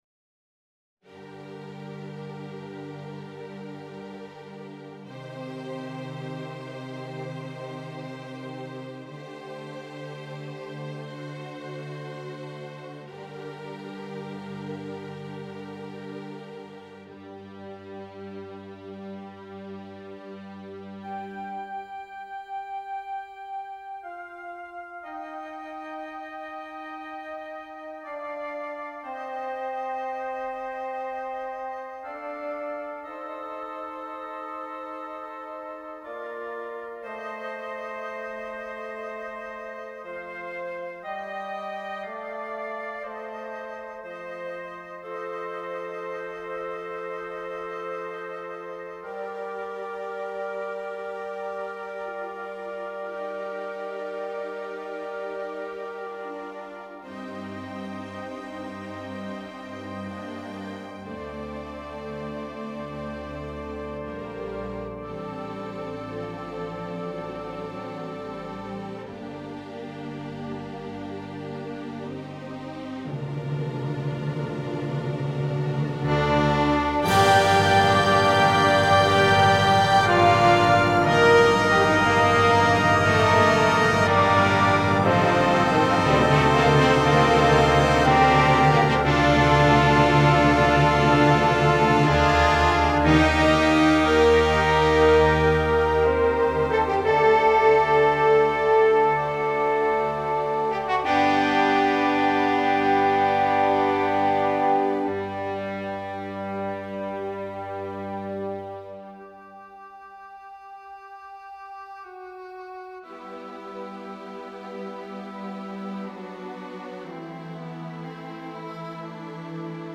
This is a "light" music symphony. It's inspired by the European danceable music.